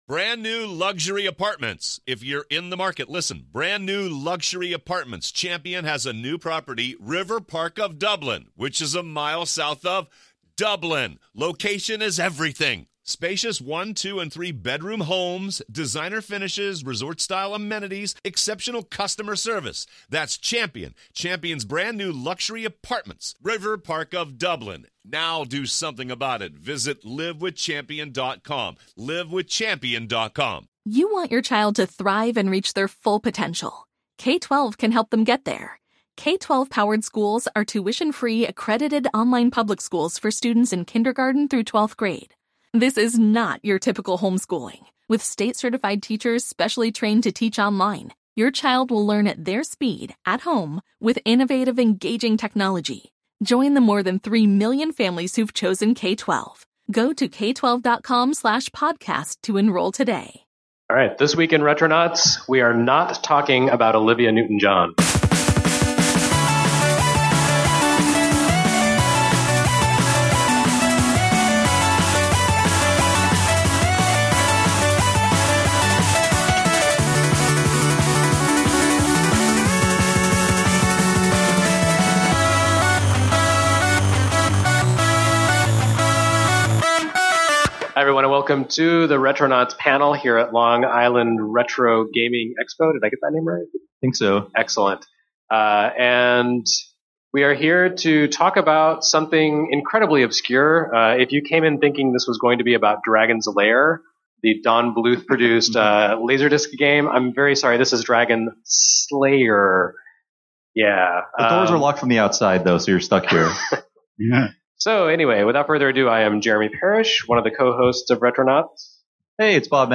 Fresh from Long Island Retro Gaming Expo